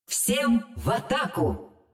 友方释放语音